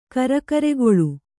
♪ karakaregoḷu